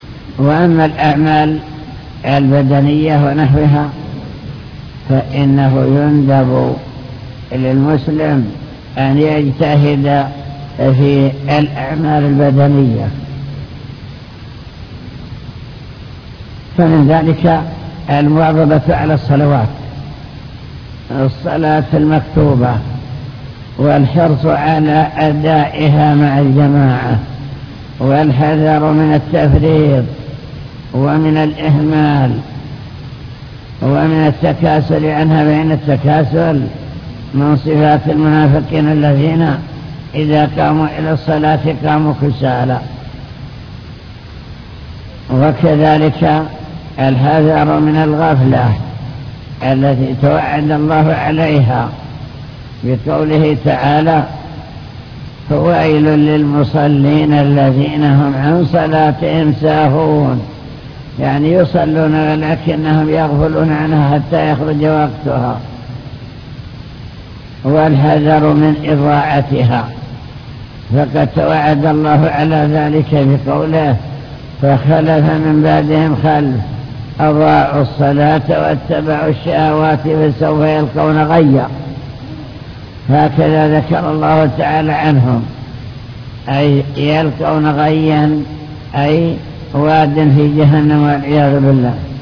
المكتبة الصوتية  تسجيلات - لقاءات  اللقاء المفتوح